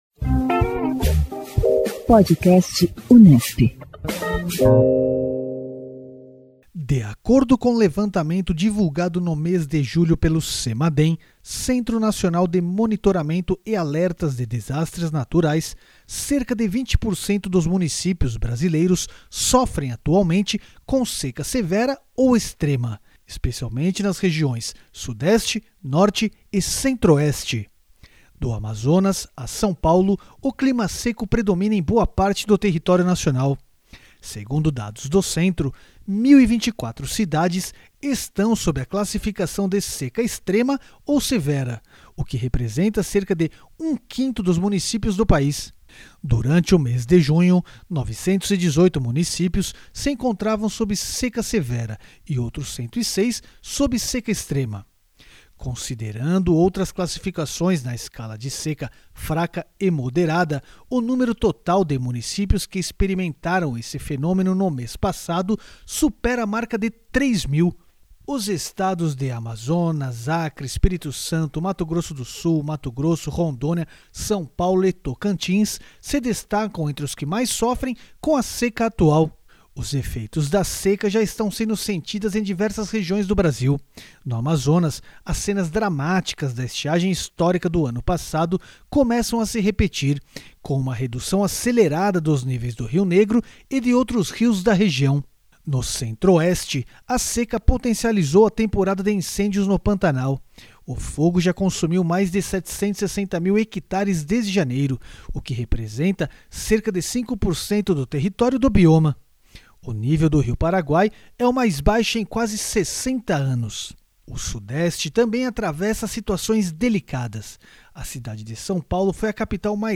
O Podcast Unesp, em parceria com a Área de Hidráulica e Irrigação do Câmpus de Ilha Solteira da Unesp, publica semanalmente noticiário sobre a agricultura irrigada e agroclimatologia.